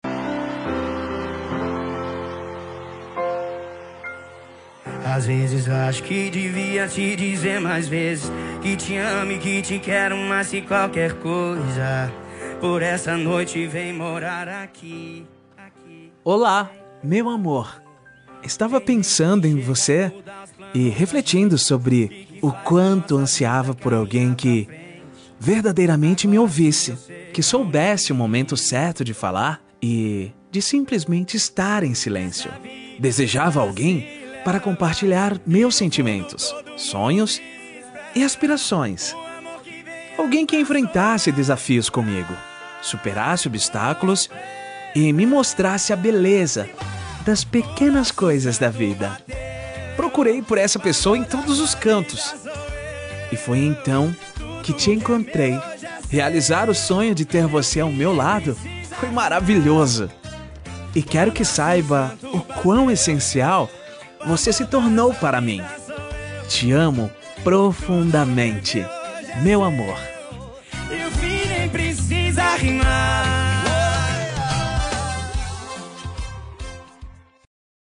Telemensagem Romântica – Voz Masculina – Cód: 911501